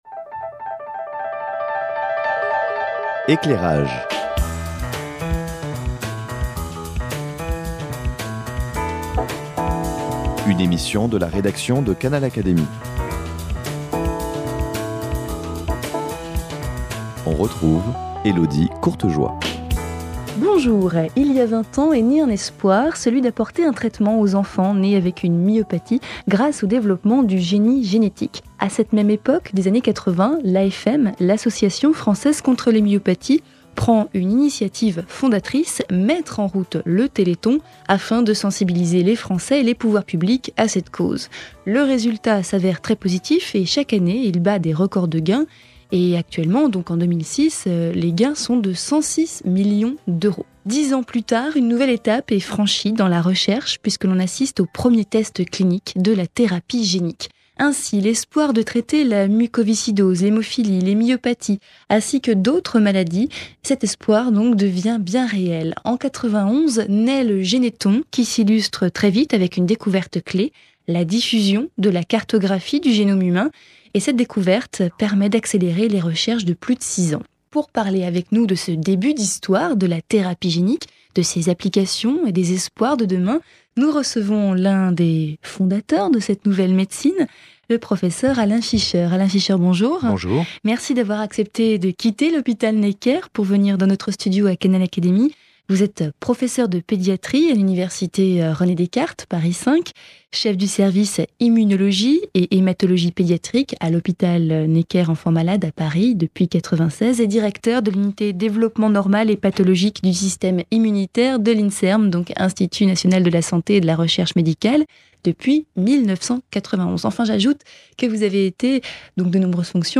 L’immunologiste et académicien Alain Fischer nous livre les prémices de l’histoire de la thérapie génique.